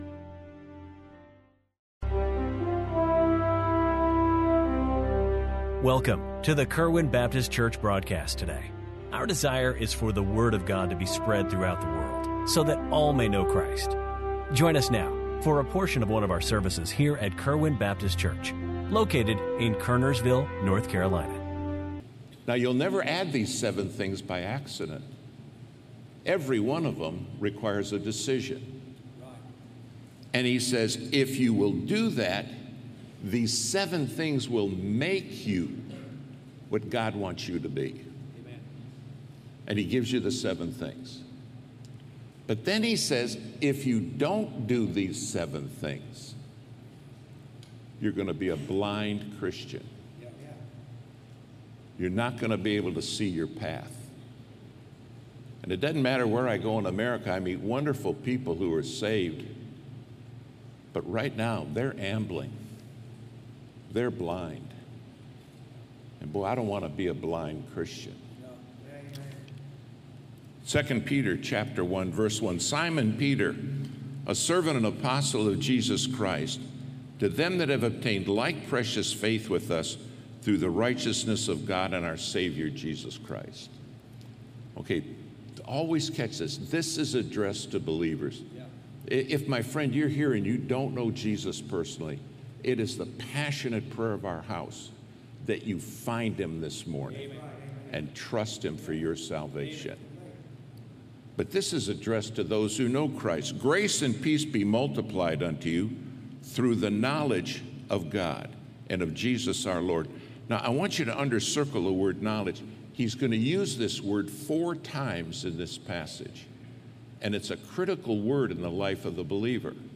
Kerwin Baptist Church Daily Sermon Broadcast